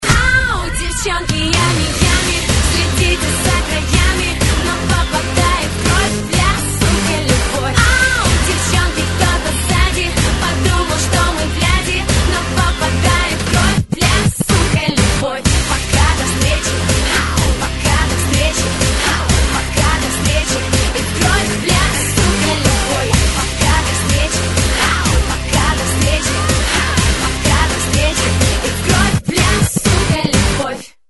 матерный припев песни